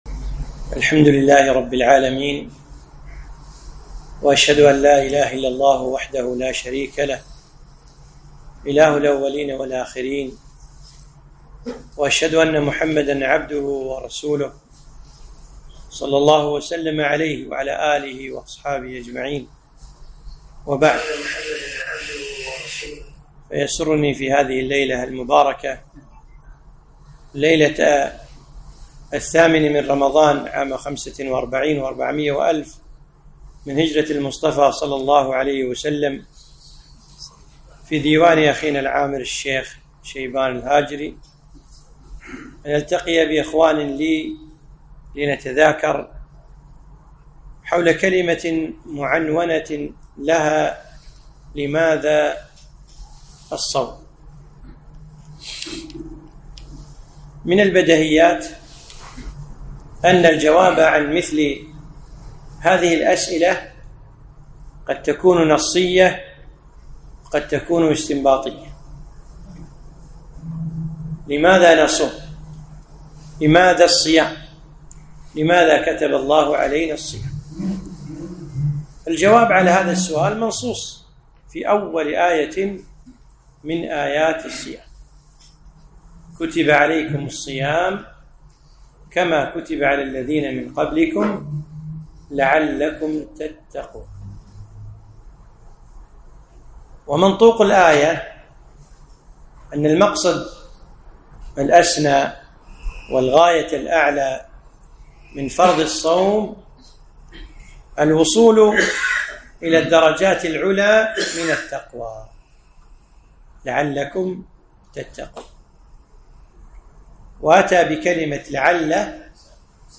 محاضرة - لماذا الصيام؟